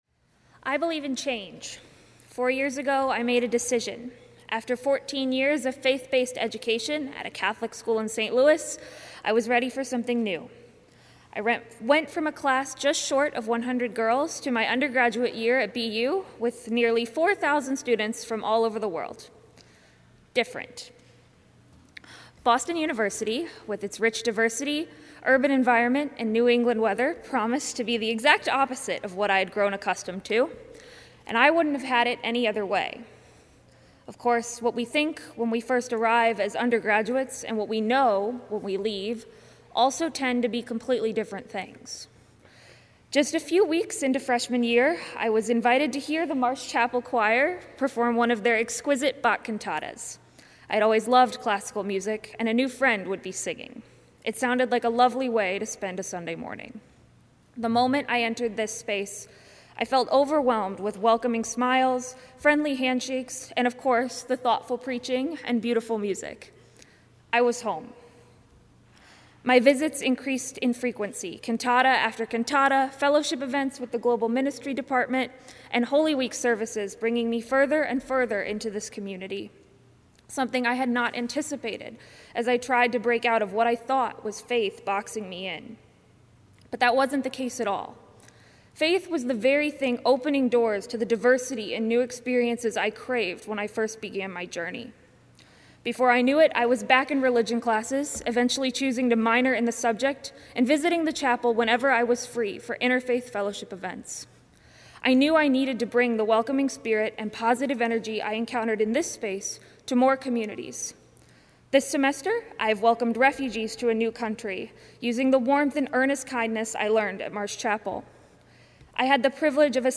Graduating students share personal stories during Marsh Chapel’s “This I Believe” Sunday